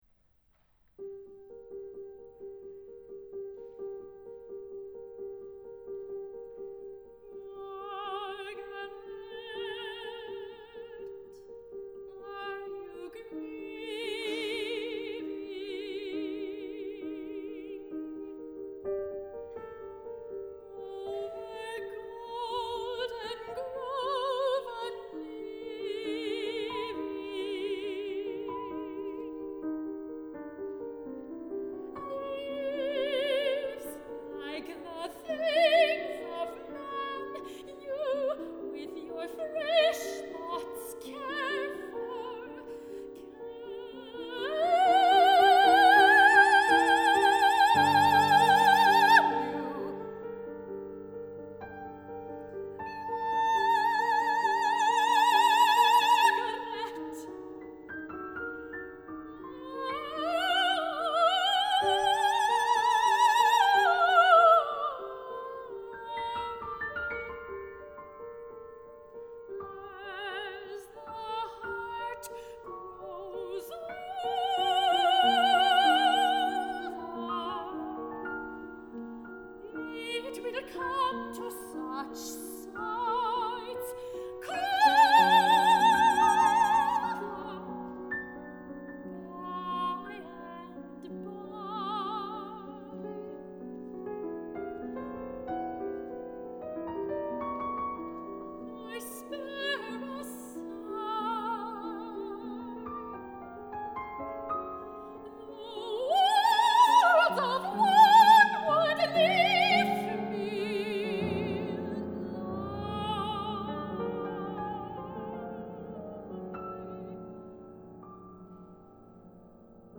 (world premiere)
Seiji Ozawa Hall, Tanglewood